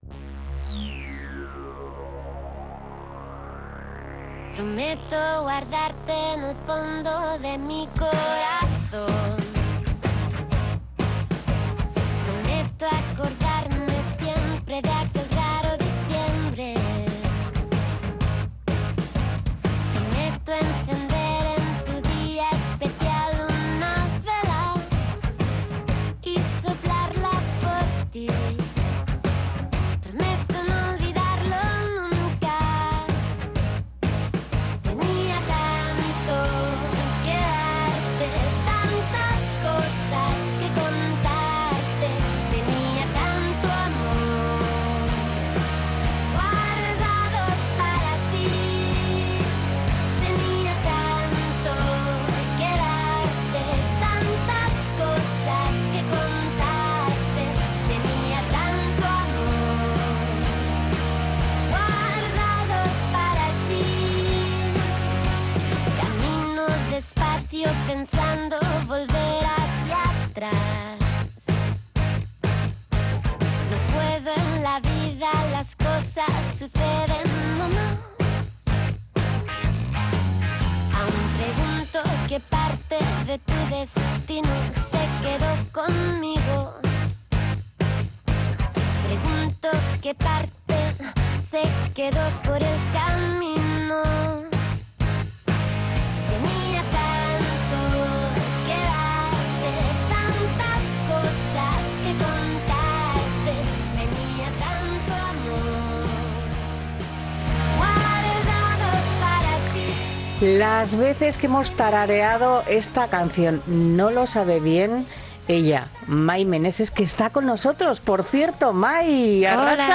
Mai Meneses repasa su vida, la relación de amor-odio con la música, una historia muy interesante que acompañamos con su discografía en una charla en la que nos abre su corazón.